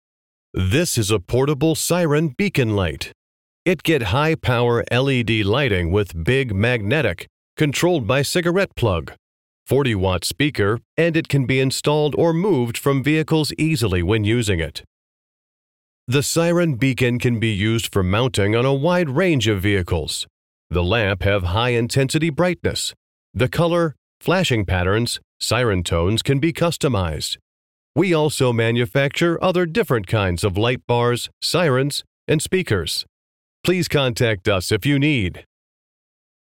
New design portable siren beacon sound effects free download